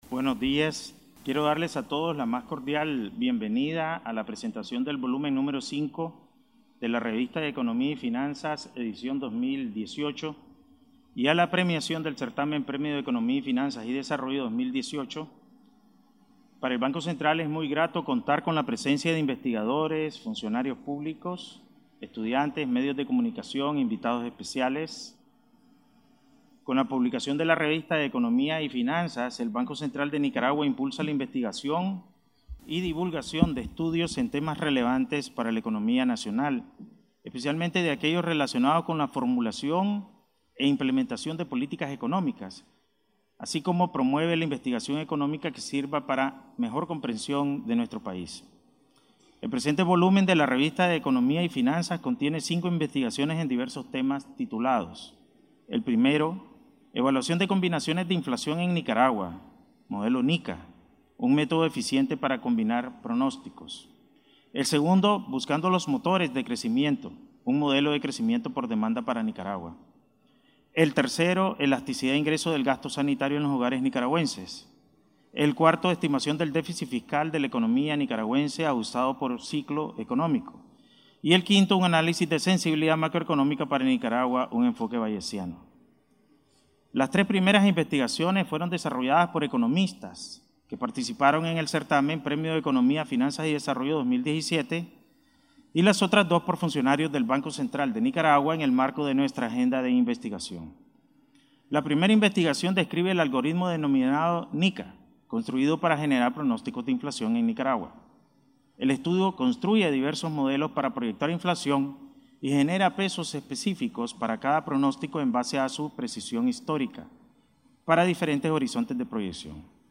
El Presidente del Banco Central de Nicaragua (BCN), Cro. Ovidio Reyes, entregó el 22 de noviembre de 2018 premios a los ganadores del Certamen: “ Premio de Economía, Finanzas y Desarrollo 2018 ”, en una ceremonia realizada en el Auditorio de la Biblioteca Rubén Darío.
Palabras del Presidente del BCN, Cro. Ovidio Reyes R.